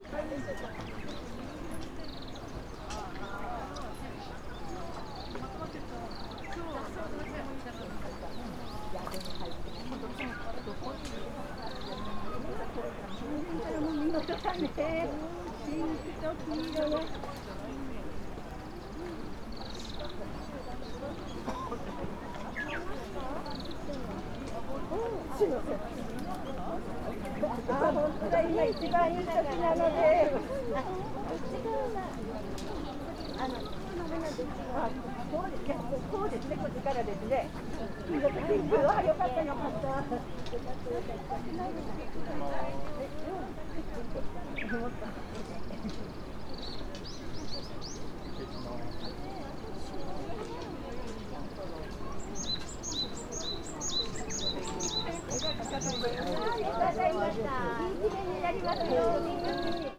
Fukushima Soundscape: Mt. Hanami